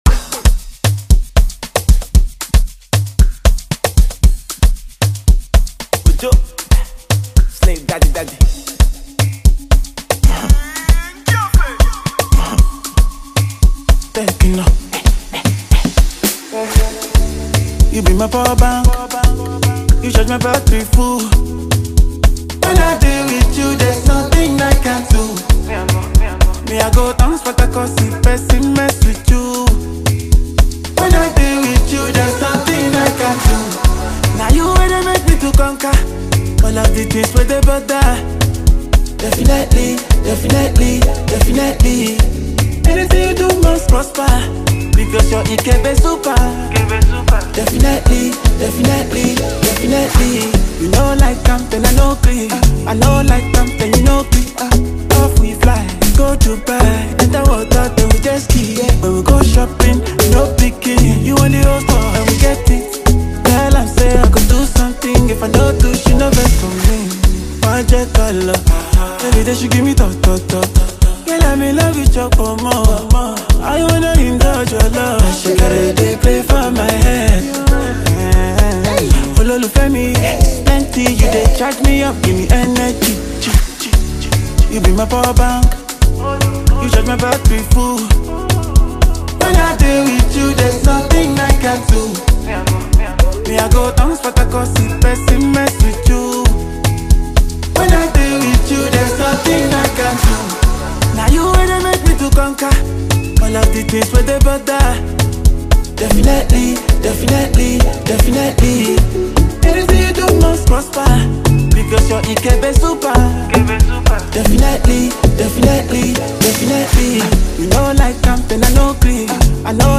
Afropop
smooth, flirtatious vocals